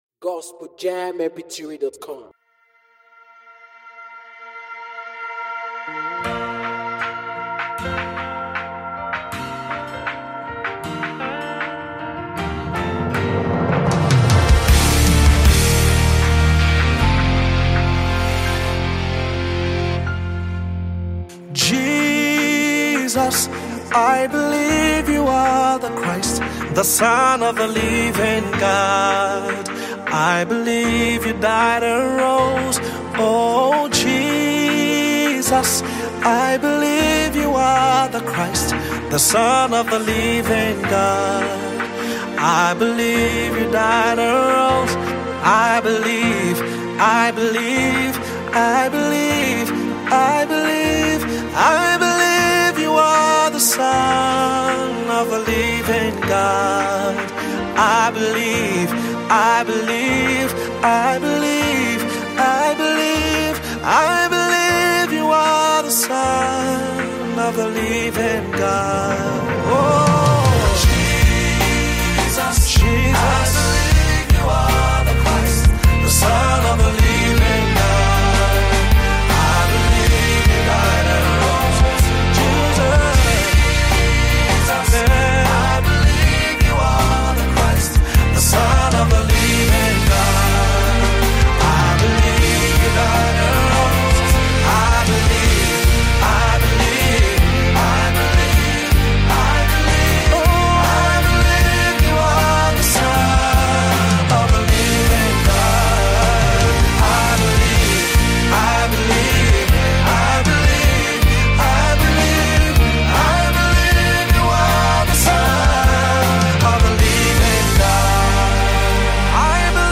Legendary Nigerian Gospel Musician